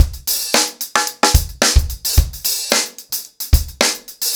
TupidCow-110BPM.57.wav